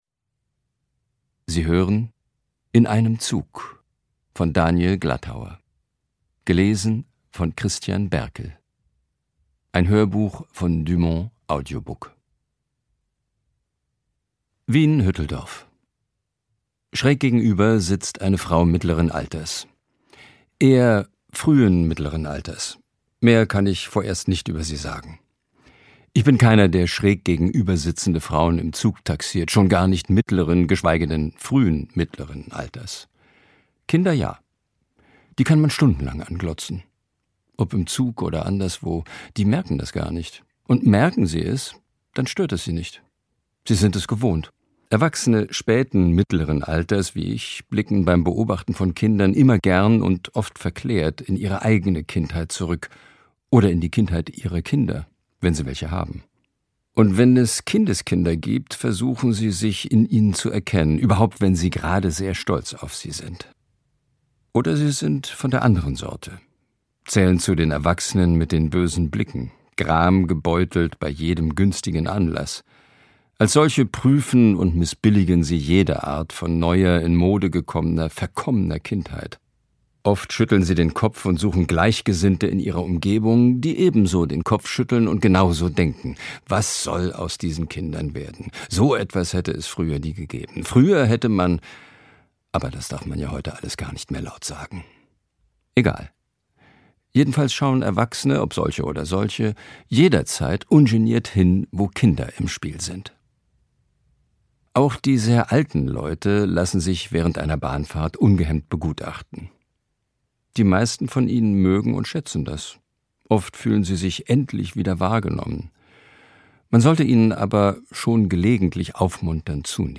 Christian Berkel (Sprecher)
Ungekürzte Lesung mit Christian Berkel